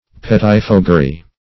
Pettifoggery \Pet"ti*fog`ger*y\, n.; pl. pettifoggeries.